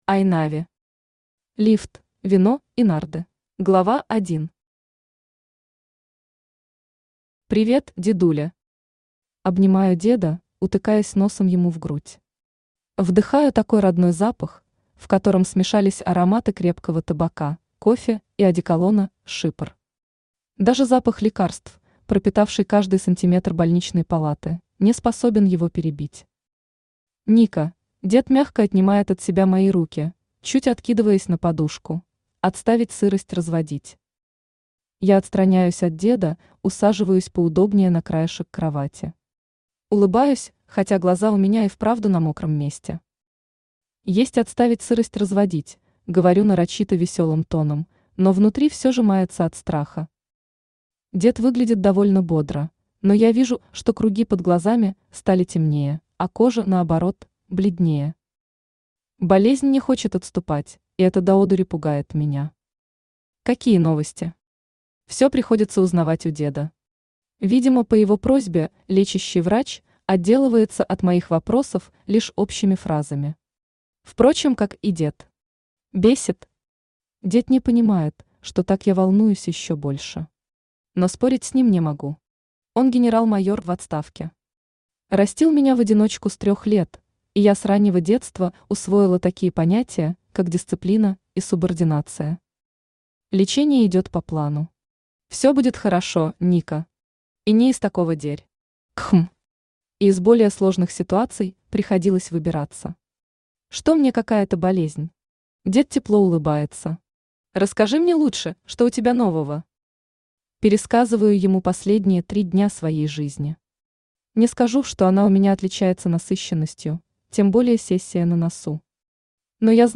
Аудиокнига Лифт, вино и нарды | Библиотека аудиокниг
Aудиокнига Лифт, вино и нарды Автор Айнави Читает аудиокнигу Авточтец ЛитРес.